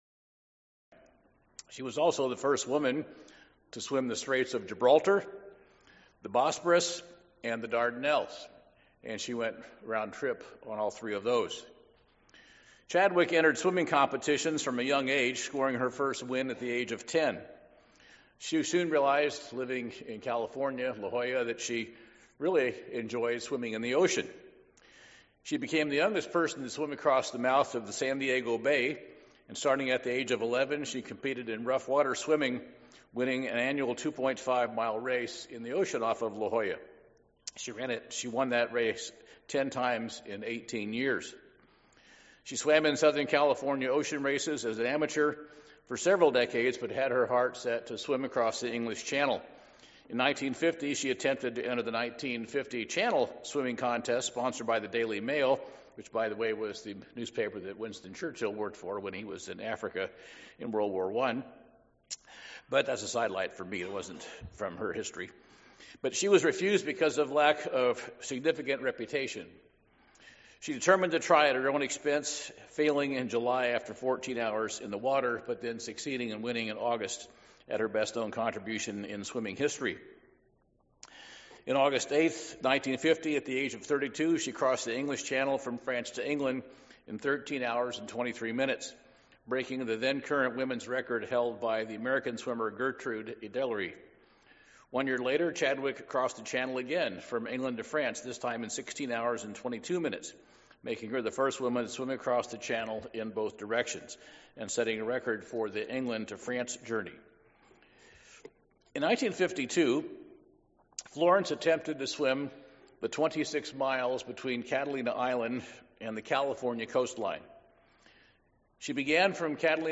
Due to technical difficulties while filming, this message picks up around thirty seconds. Florence Chadwick, an American swimmer known for her deepwater world records, overcame many obstacles. This heart-warming sermon features the analogies of overcoming and personal triumph. Like Chadwick, we must remain focused to receive our prize.